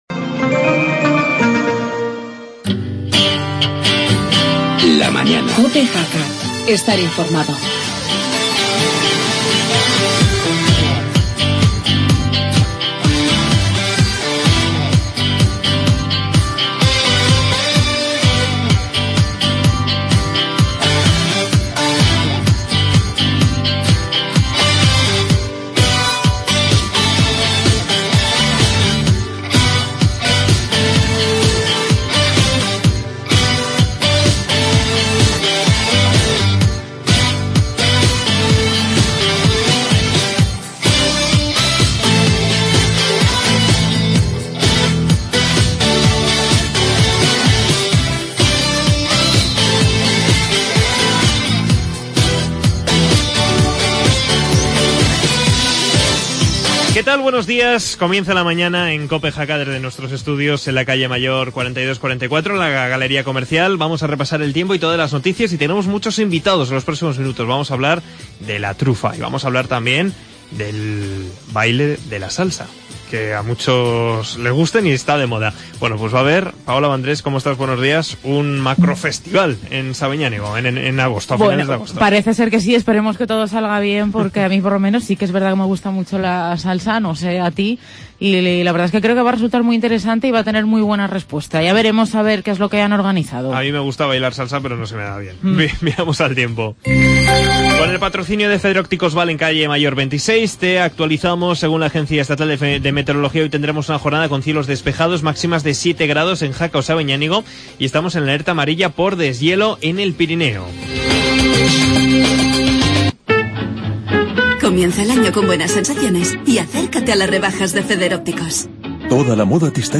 AUDIO: Actualidad,entrevista